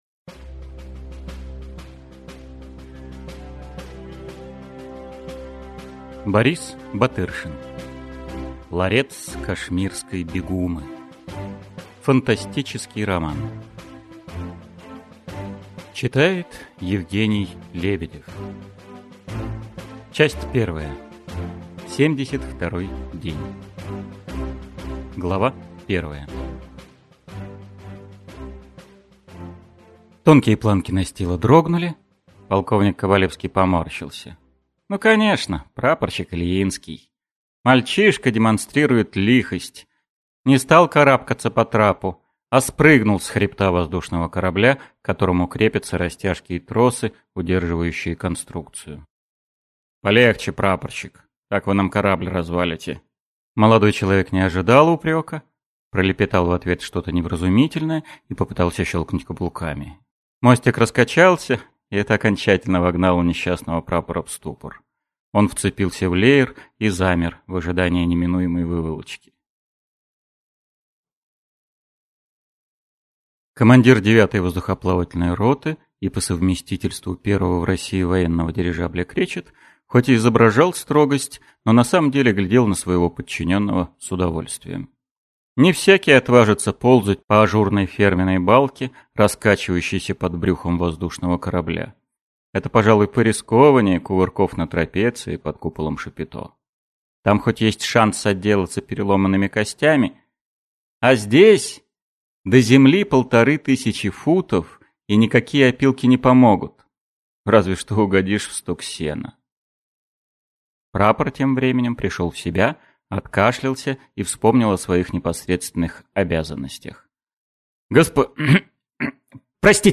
Аудиокнига Ларец кашмирской бегумы | Библиотека аудиокниг